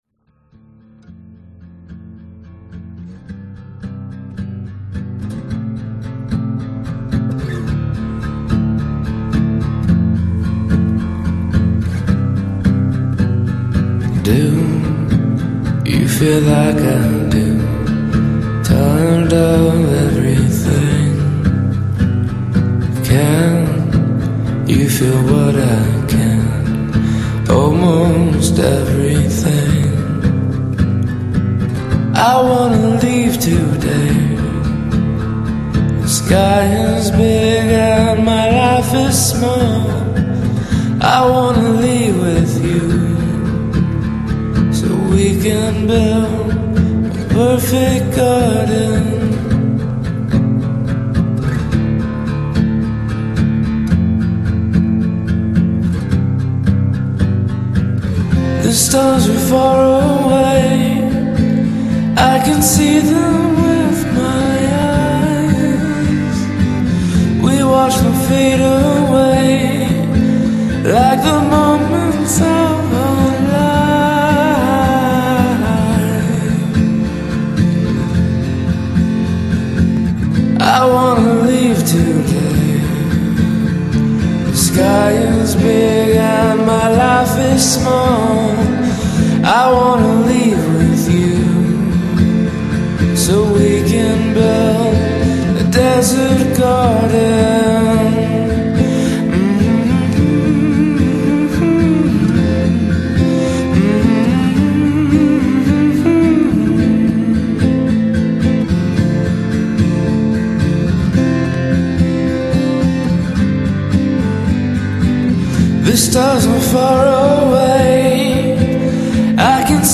c'est une voie d'homme non? t'as pas un extrait?